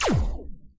Erekir unit SFX
bolt.ogg